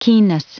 Prononciation du mot keenness en anglais (fichier audio)
Prononciation du mot : keenness